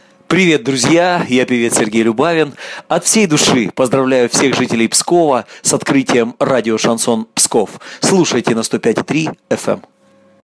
Певец Сергей Любавин поздравил радио «Шансон» с началом вещания в Пскове.